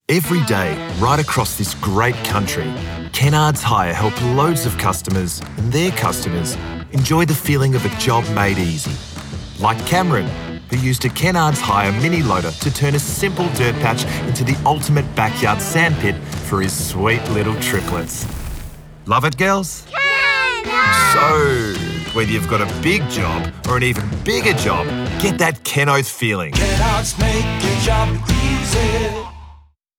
37 Year old Australian Male with a knack for accents
Radio Commercial Australia
English - Australian
Young Adult